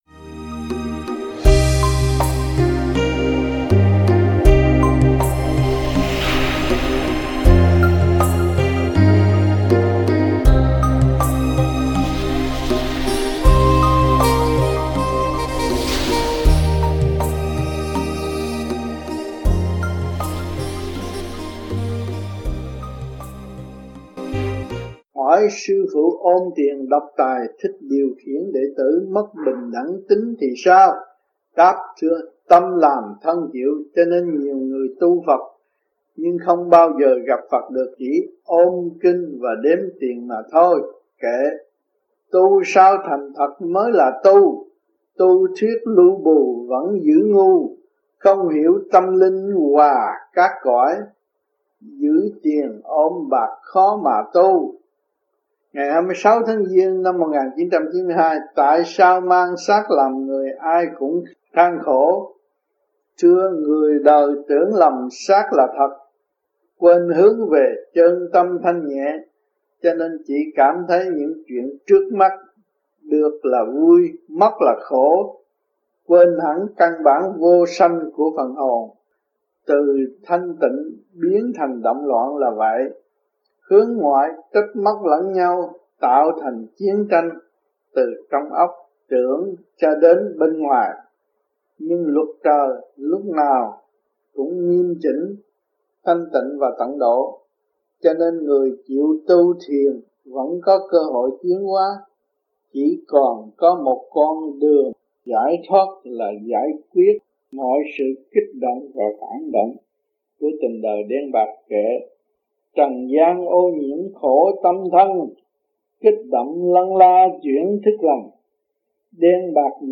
THUYẾT GIẢNG